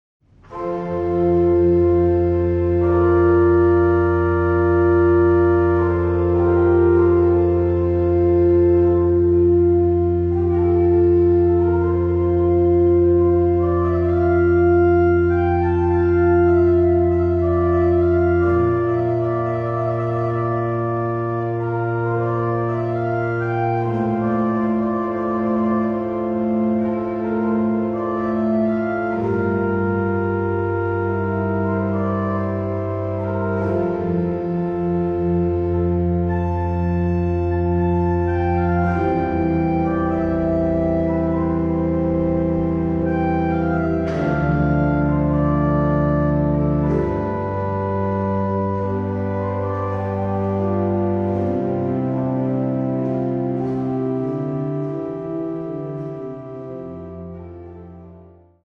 Organo "Amedeo Ramasco", Chiesa Parrocchiale di Crocemosso
Organo